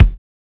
LO FI 5 BD.wav